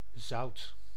Ääntäminen
Ääntäminen : IPA: [zʌut]